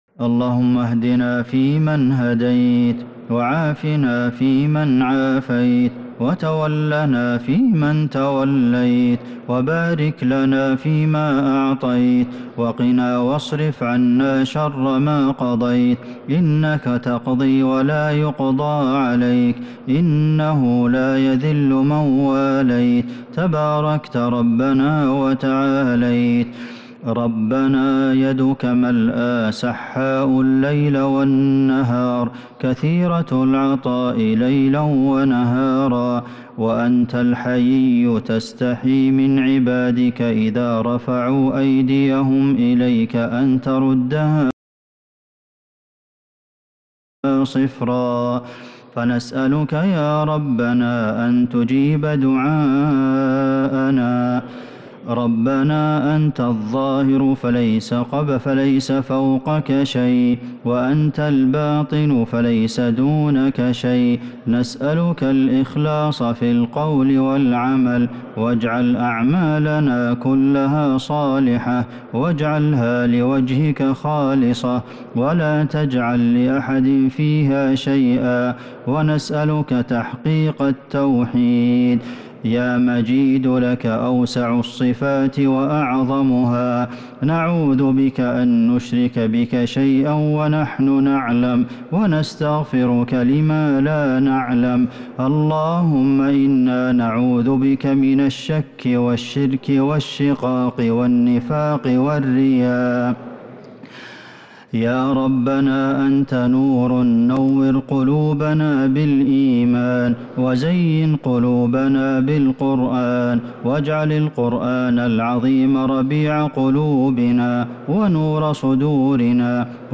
دعاء القنوت ليلة 29 رمضان 1443هـ | Dua for the night of 29 Ramadan 1443H > تراويح الحرم النبوي عام 1443 🕌 > التراويح - تلاوات الحرمين